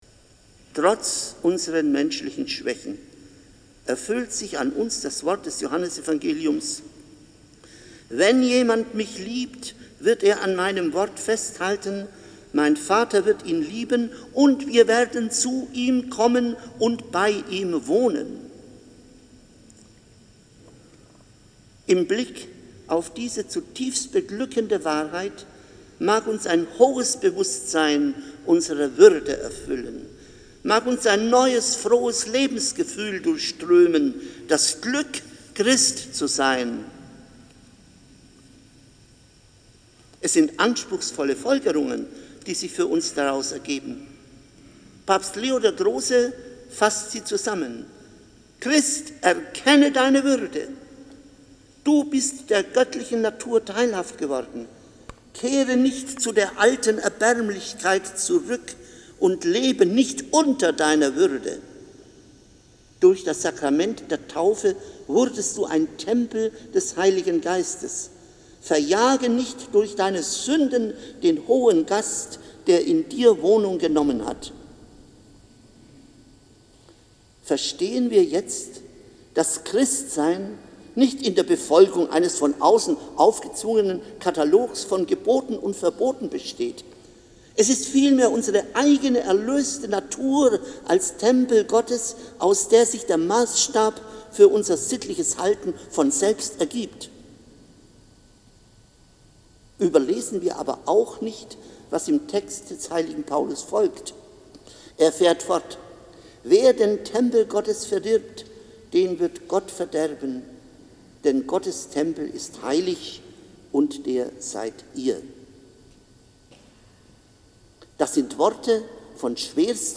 Predigt als mp3